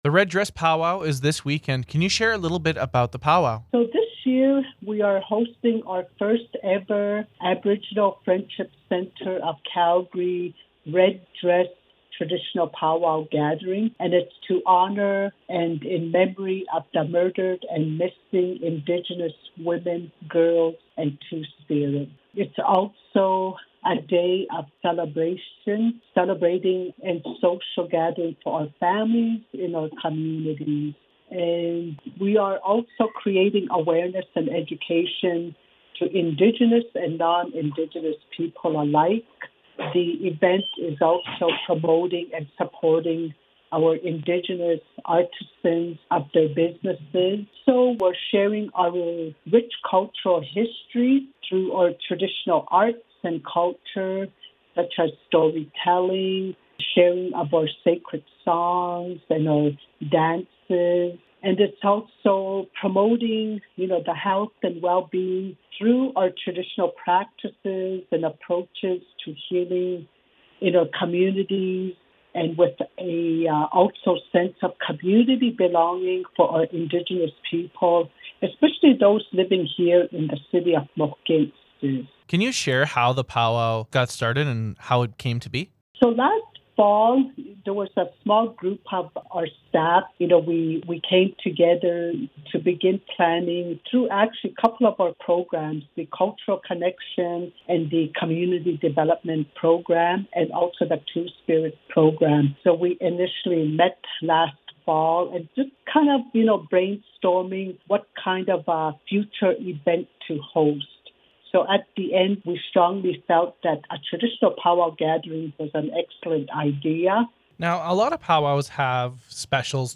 red-dress-powwow-voicer.mp3